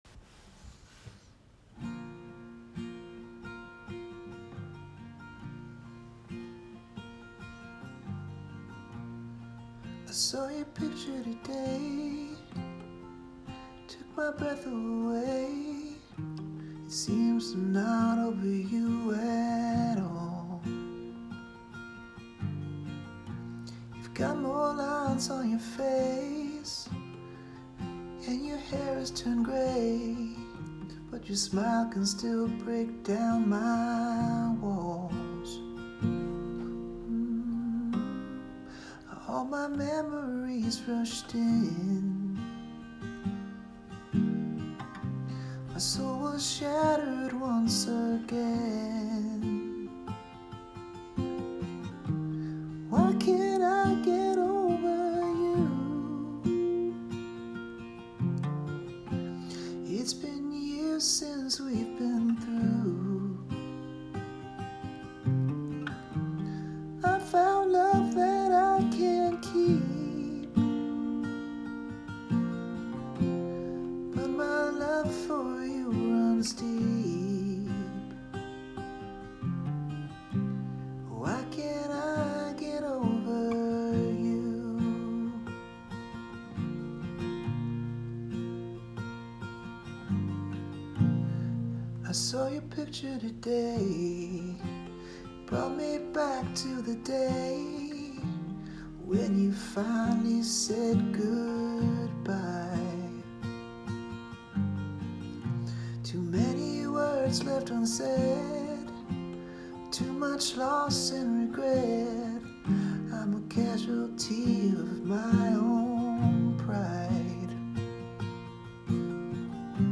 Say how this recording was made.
rough demo.